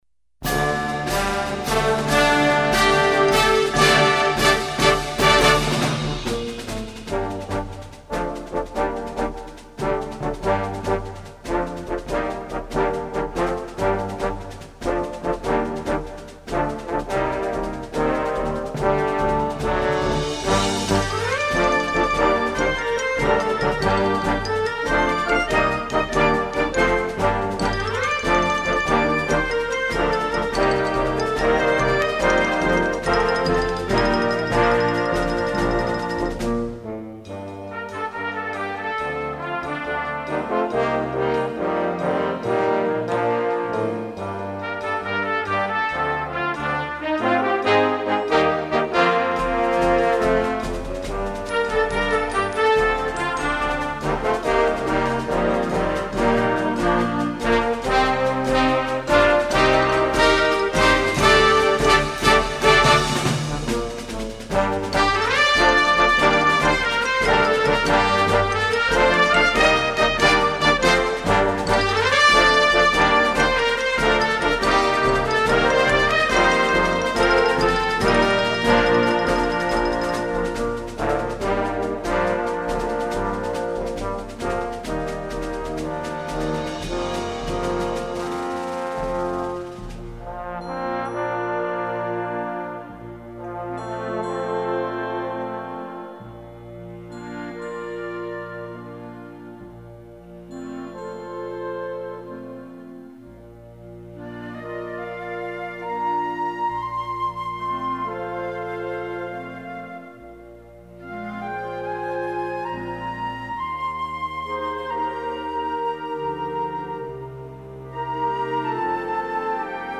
Gattung: Konzertante Blasmusik
5:15 Minuten Besetzung: Blasorchester Zu hören auf